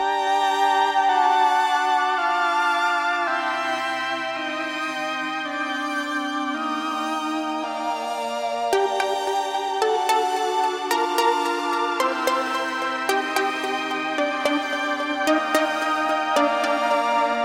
标签： 110 bpm Weird Loops Synth Loops 2.94 MB wav Key : G
声道立体声